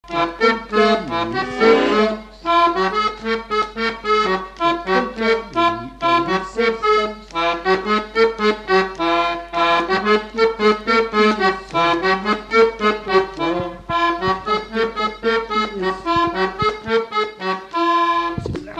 Mémoires et Patrimoines vivants - RaddO est une base de données d'archives iconographiques et sonores.
Résumé instrumental
gestuel : danse
Pièce musicale inédite